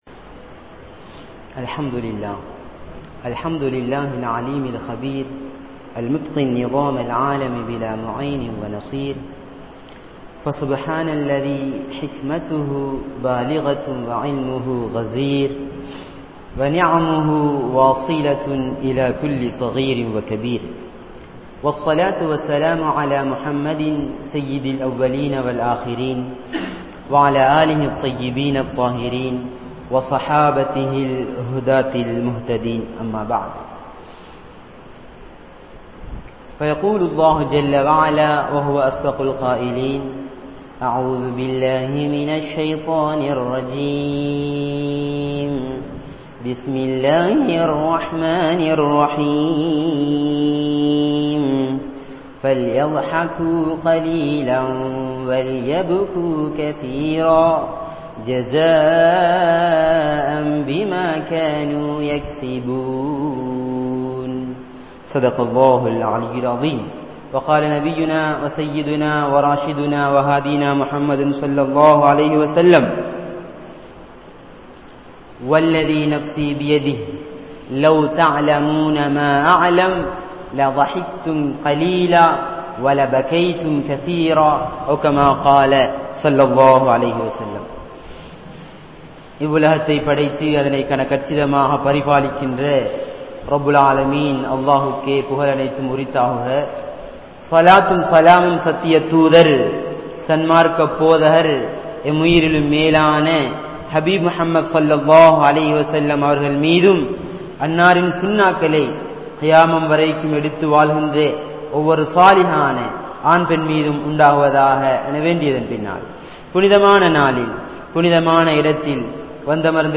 Sariyaavin Paarvaiel Sirrippu (ஷரிஆவின் பார்வையில் சிரிப்பு) | Audio Bayans | All Ceylon Muslim Youth Community | Addalaichenai
Gorakana Jumuah Masjith